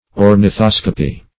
Search Result for " ornithoscopy" : The Collaborative International Dictionary of English v.0.48: Ornithoscopy \Or`ni*thos"co*py\, n. [Ornitho- + -scopy: cf. Gr.